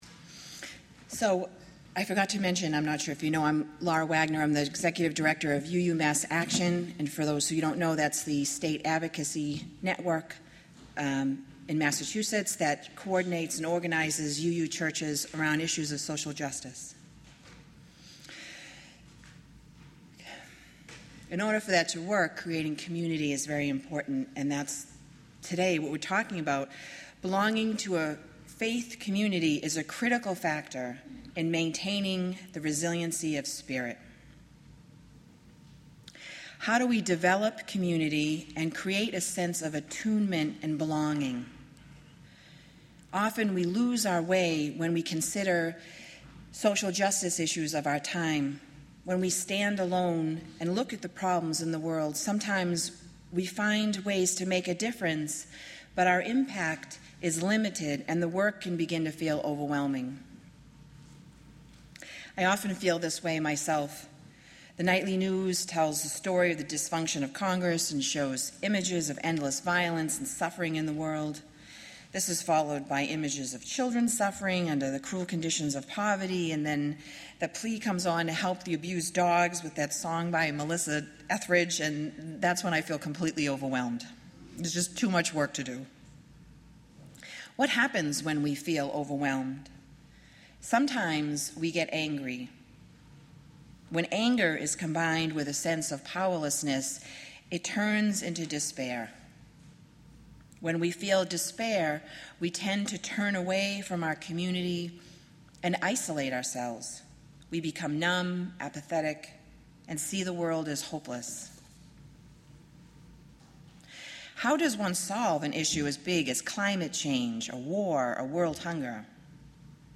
leads this service exploring what it means to be part of a faith community. How do we support one another in finding peace? Living in community is an essential part of resilience.